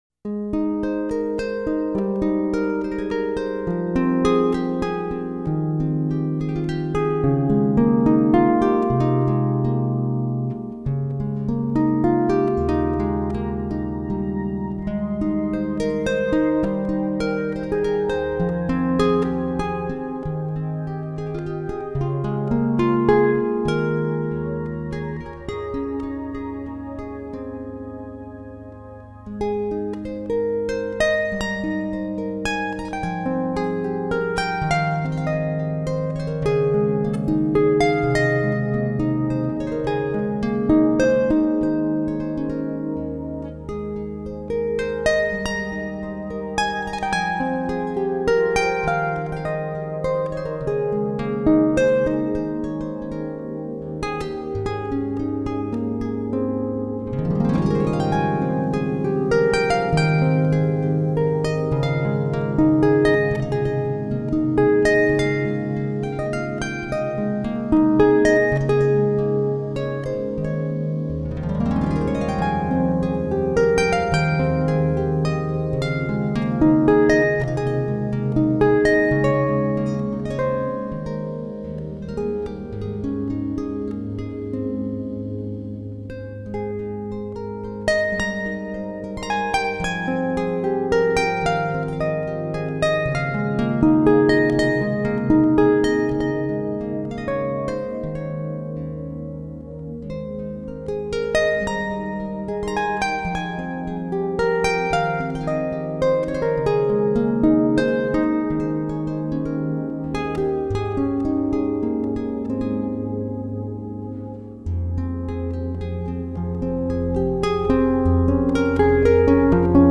它以New Age的编曲手法,将竖琴优雅宁谧的古典气质分表现了出来,听来令人心旷神怡,有漫步仙境之感。
录音是发烧级数。